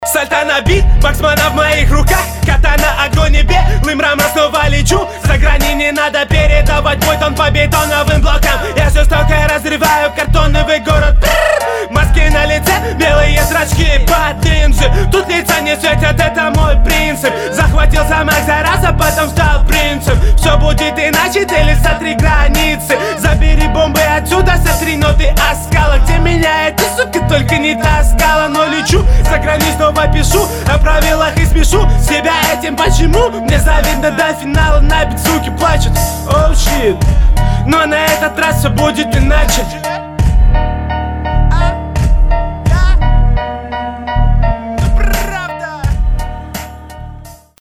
Зря так разделяешь слова, это не добавляет ритмике обаяния, а динамику разрывает. В подаче ты слишком криклив и всё исполнение на одной ноте, хоть и пытаешься раскладывать